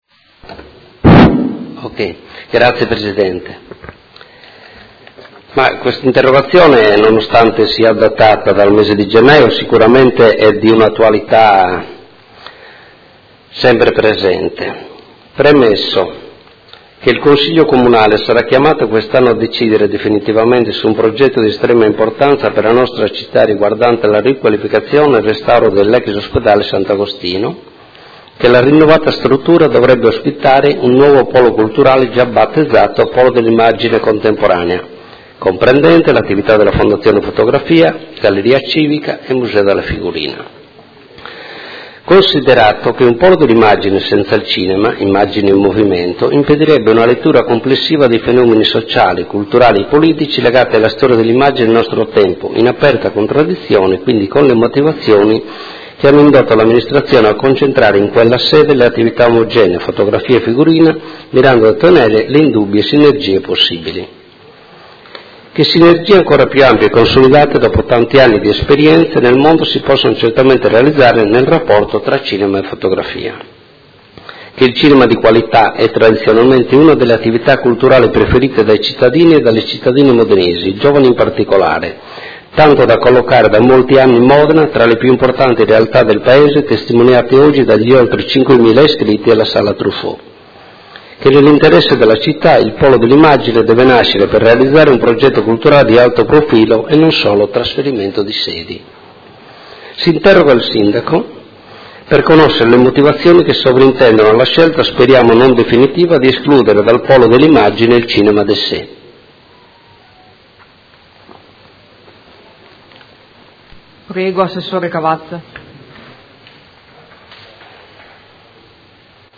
Seduta del 28/09/2017 Interrogazione del Consigliere Cugusi (SEL), del Consigliere Rocco (FaS-SI) e del Consigliere Campana (Gruppo Per me Modena), avente per oggetto: Quale futuro per il Cinema d’essai?